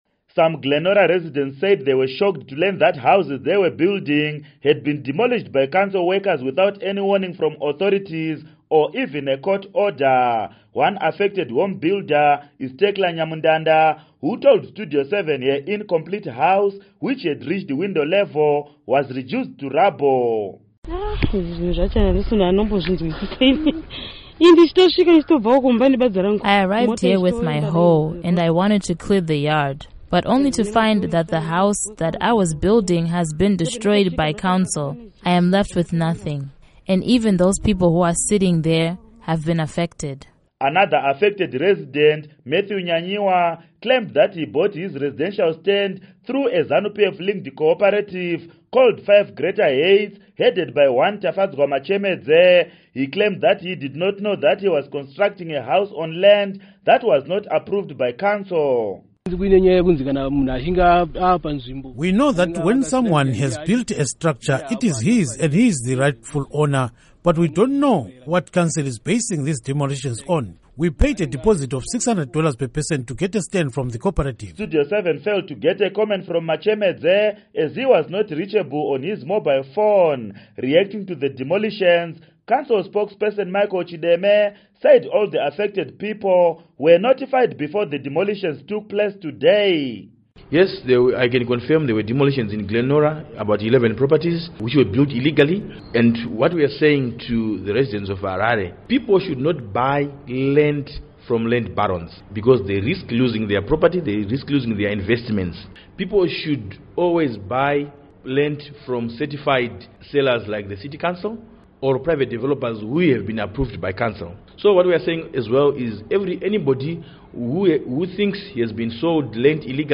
Report on Demolition of Houses